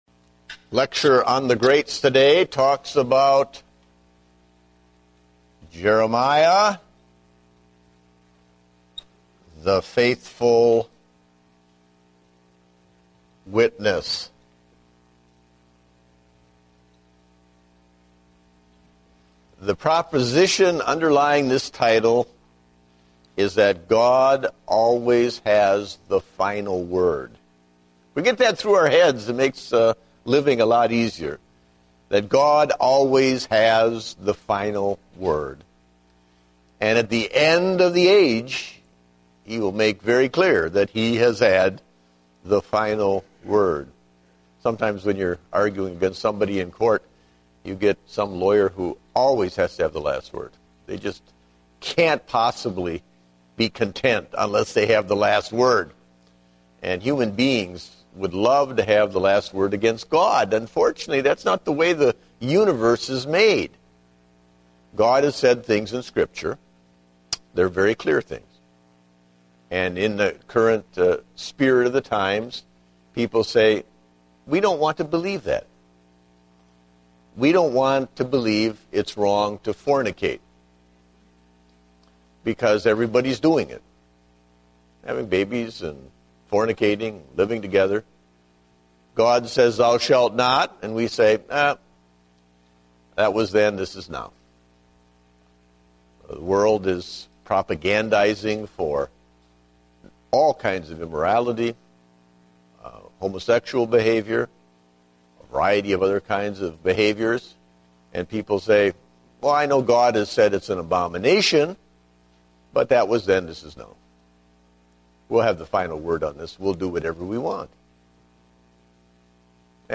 Date: April 18, 2010 (Adult Sunday School)